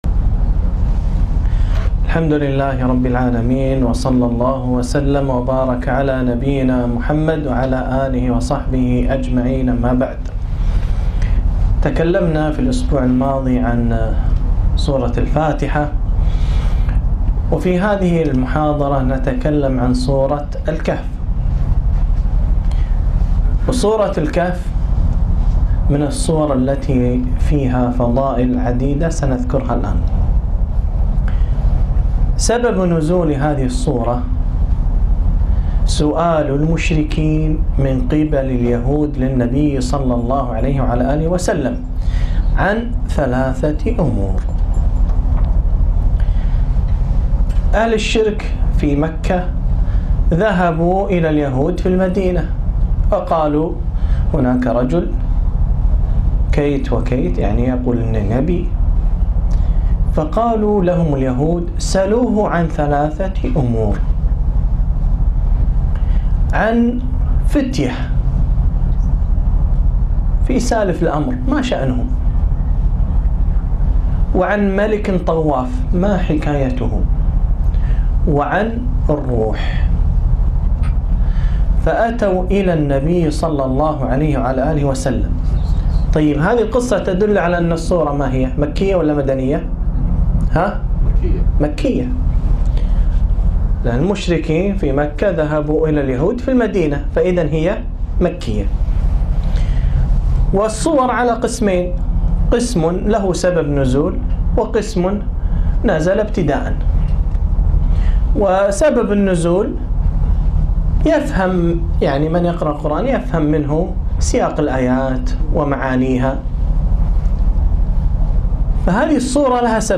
محاضرة - تأملات في سورة الكهف - دروس الكويت